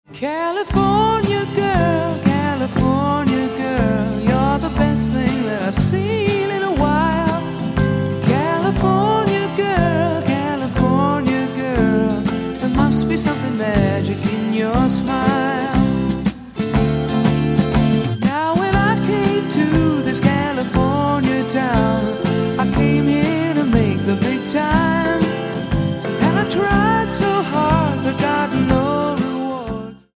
bass, vocals
drums, percussion
guitar, vocals, solina, piano
Album Notes: Recorded at Can-Base Studios, Vancouver, Canada